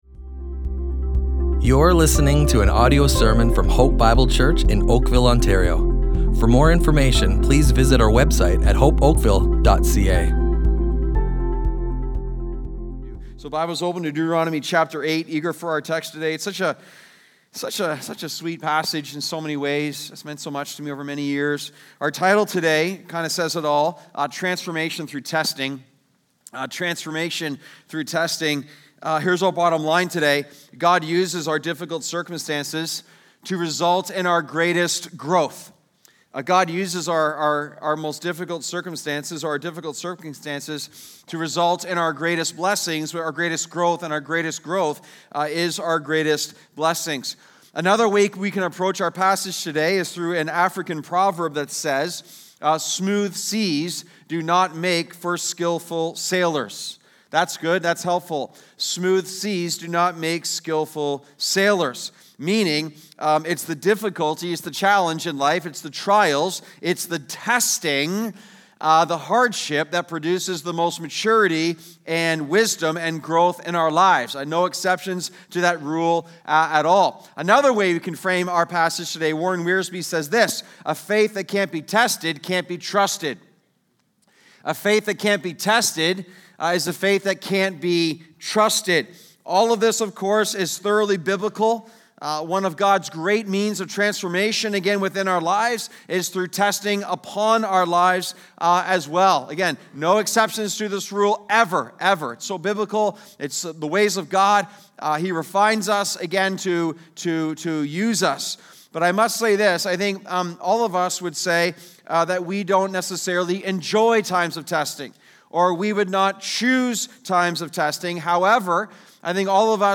Hope Bible Church Oakville Audio Sermons Listen and Love // Transformation Through Testing!